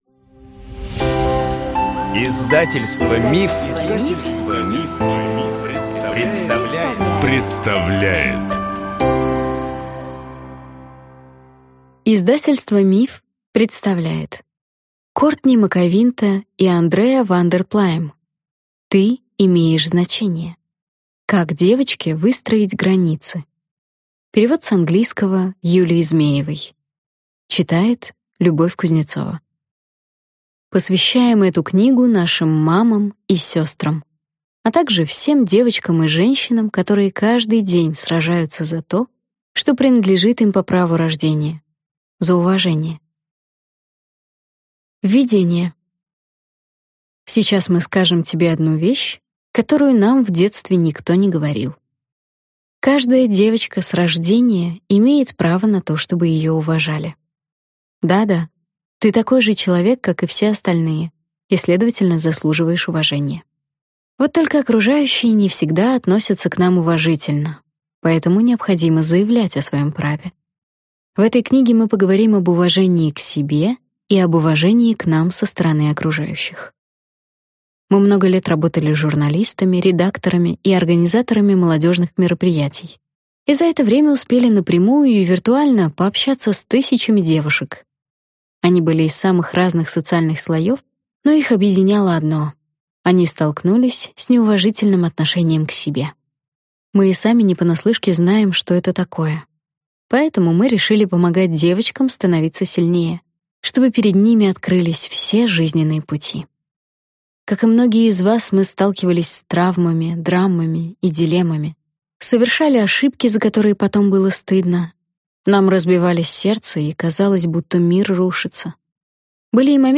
Aудиокнига Ты имеешь значение!